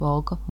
pronunciation; German: Walk) is a town and municipality in the Vidzeme region of Latvia, on the border with Estonia along both banks of the river Pedele.
Lv-Valka.ogg.mp3